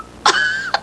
PIANETA GRATIS - Audio Suonerie - Persone - Tosse 02
COUGH_CR.WAV